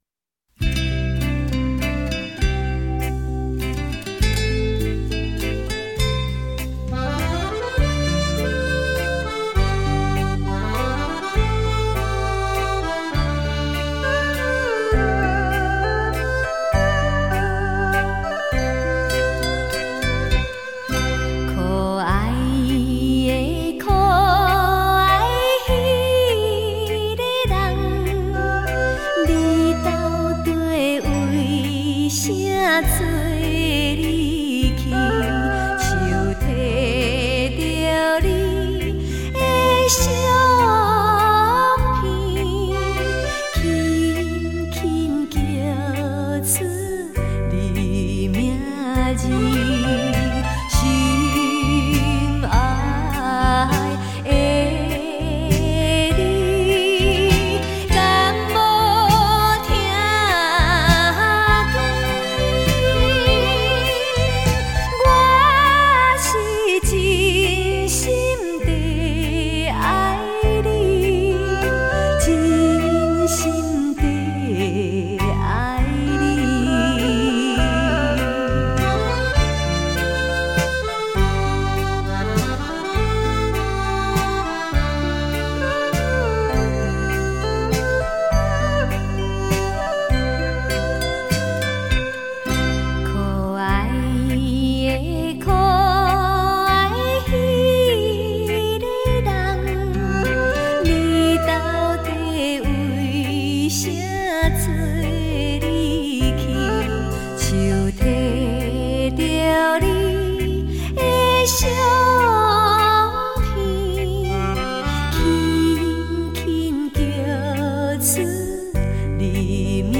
舞厅规格
华尔兹歌唱版
将自己投入感性的歌声中